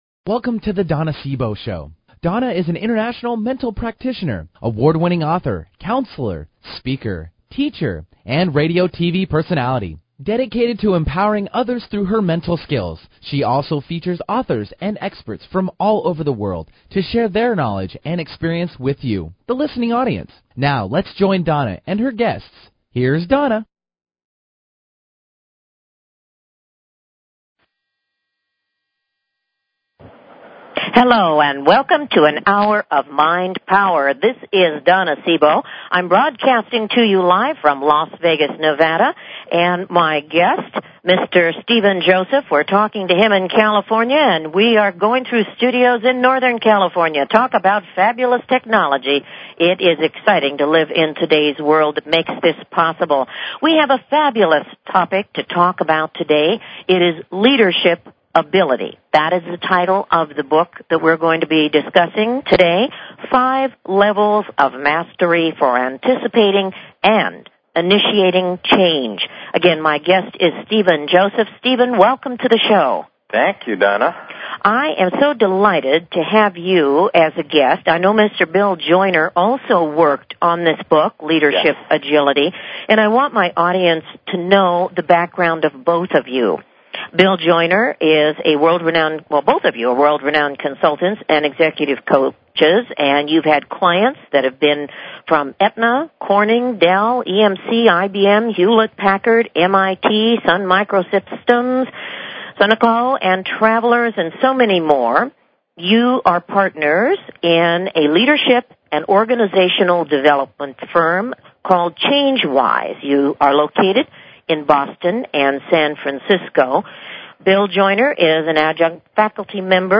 Talk Show Episode
Callers are welcome to call in for a live on air psychic reading during the second half hour of each show.